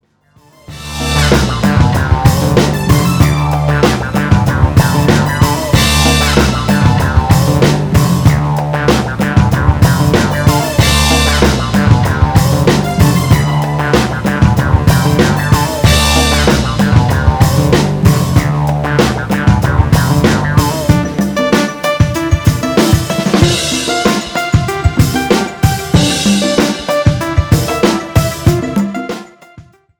FUNK  (3.37)